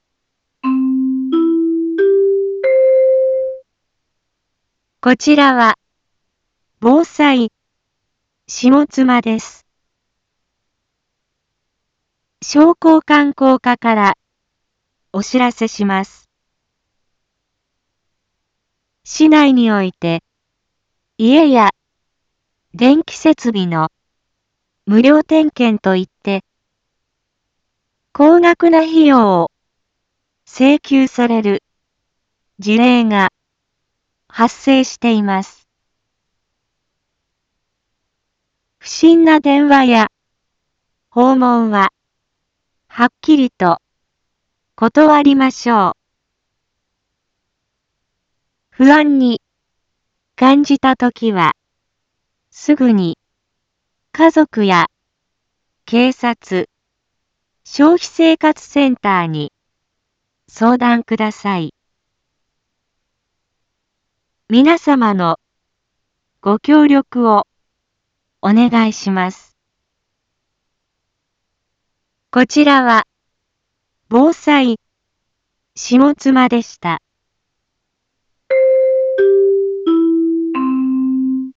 Back Home 一般放送情報 音声放送 再生 一般放送情報 登録日時：2026-01-14 12:31:42 タイトル：不審な電話や訪問販売への注意喚起について インフォメーション：こちらは、ぼうさいしもつまです。